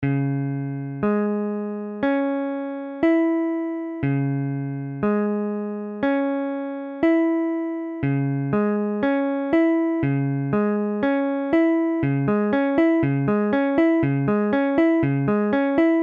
Illustration sonore : IV_Dbm.mp3
Dbm : accord de R� b�mol mineur Mesure : 4/4
Tempo : 1/4=60